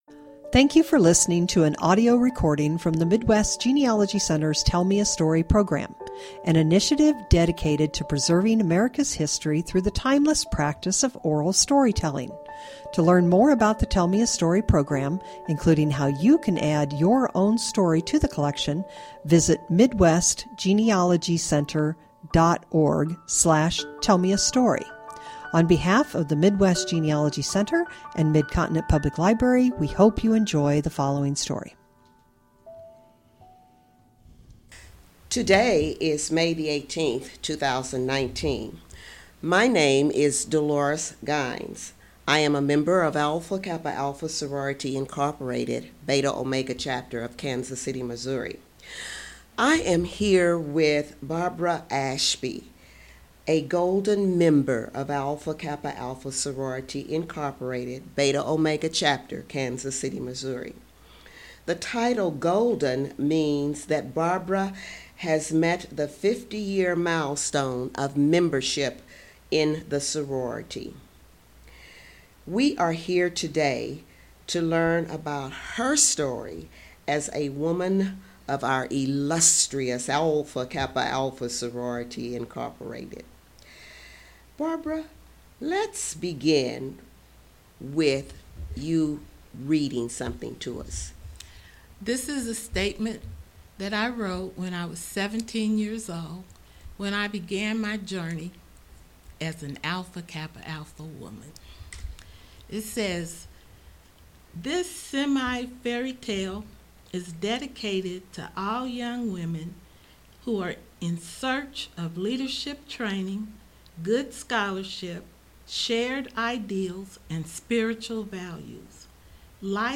Oral History
Stereo